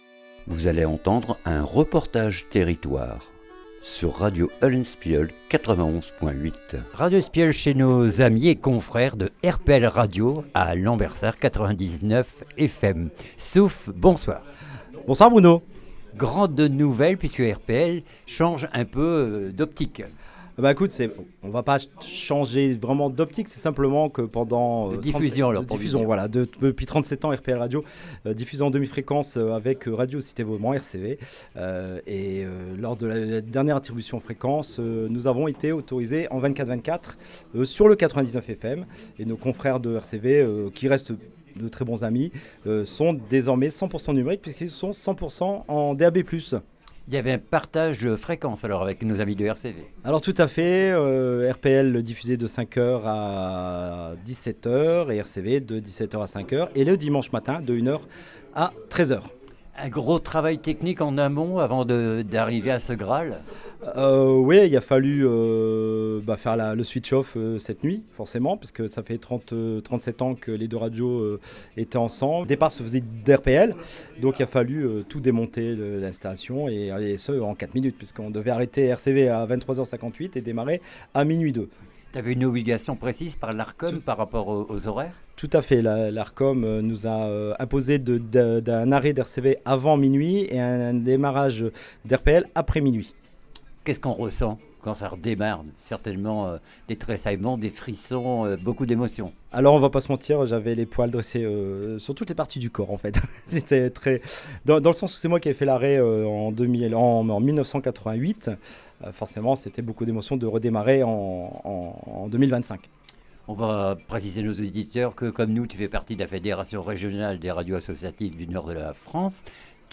REPORTAGE TERRITOIRE RPL 99FM DESORMAIS 24/24 !